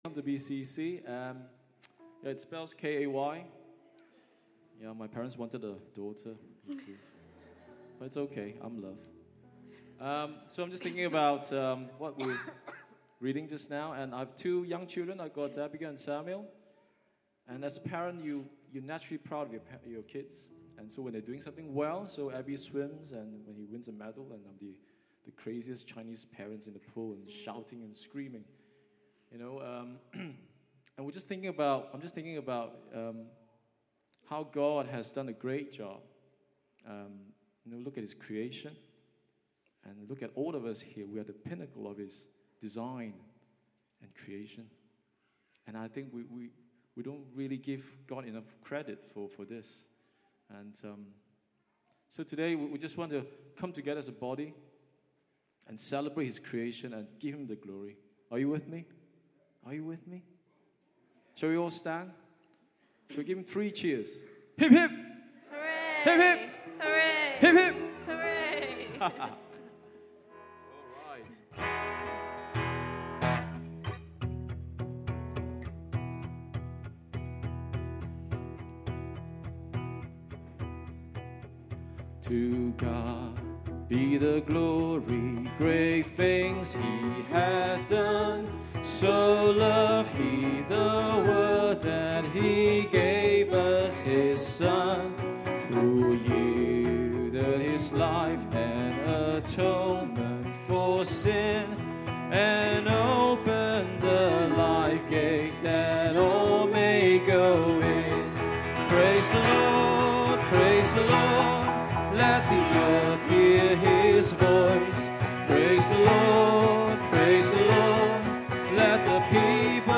Worship October 2, 2016